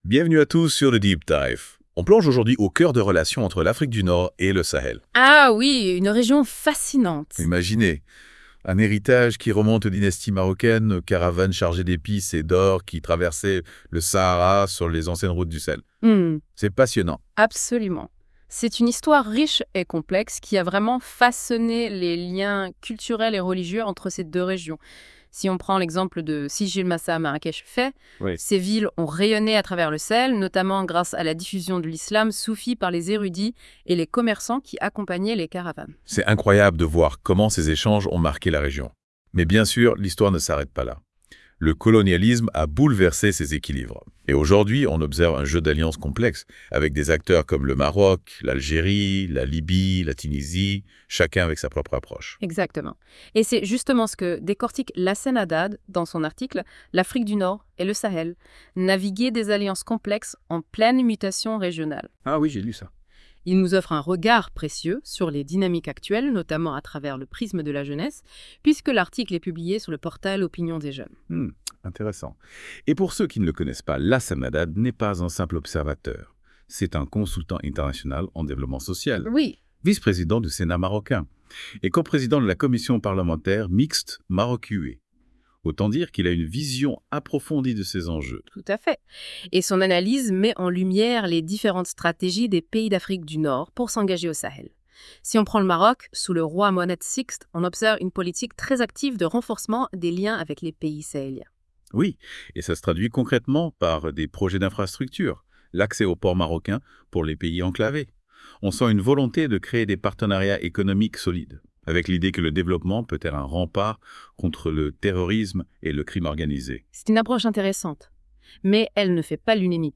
Débat - Podcast : les chroniqueurs de la Web Radio débattent des idées contenues dans cet article à travers ces questions :